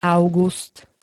Zvukové nahrávky niektorých slov
glbe-august.spx